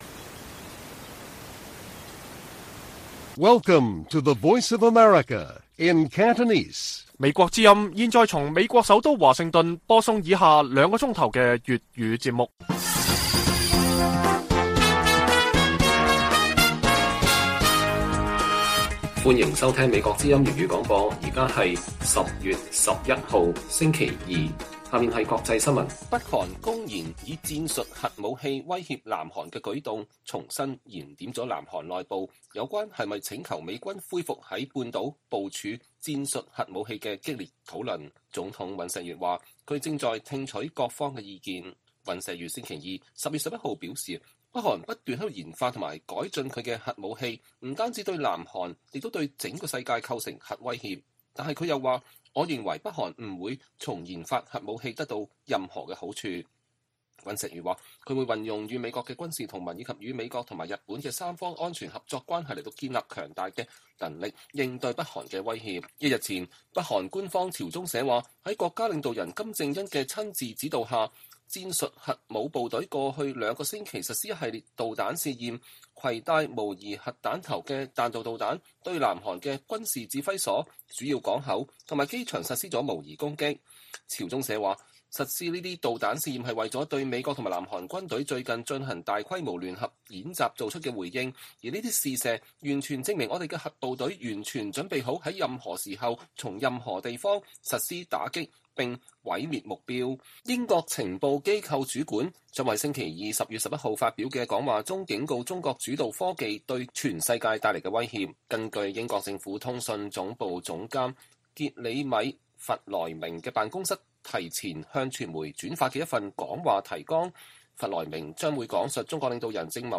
粵語新聞 晚上9-10點: 面對北韓公然核威脅 南韓討論議是否請求美國重新在朝鮮半島部署戰術核武